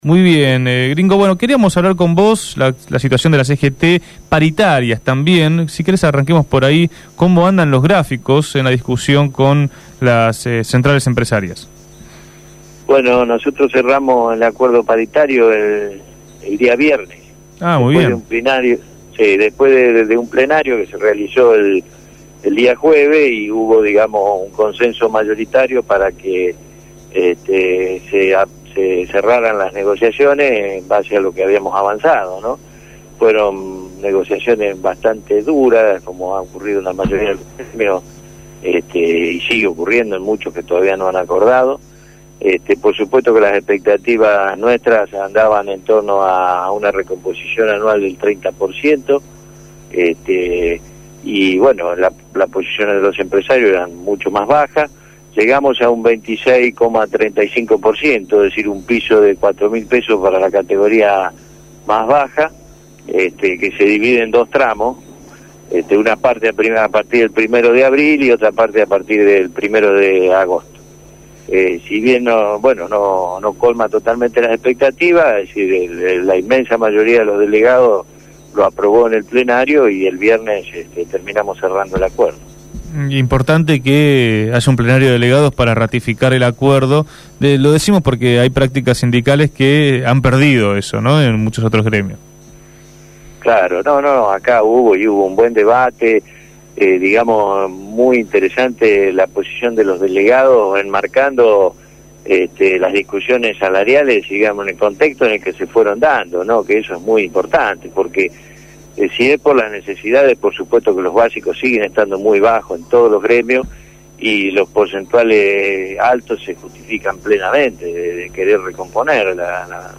habló en Punto de Partida.